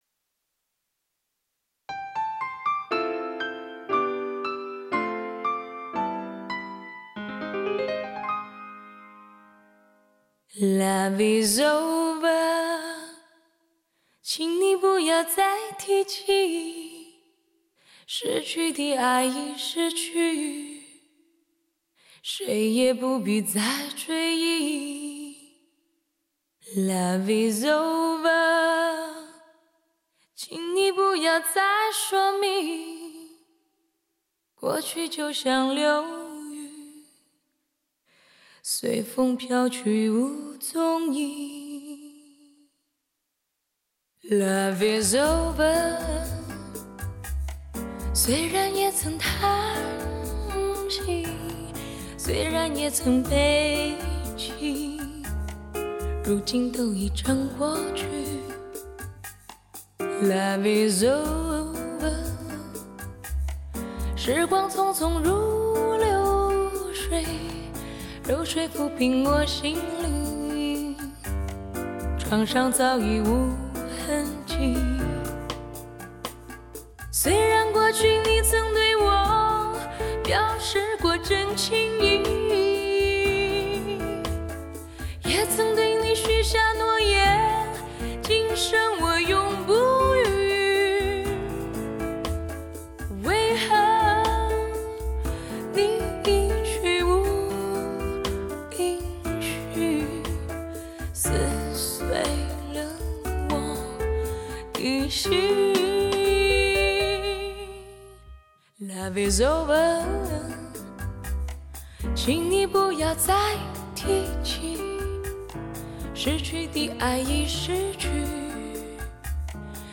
即世界首张中国民乐元素的新爵士蓝调BOSSA NOVA巴莎诺瓦专辑。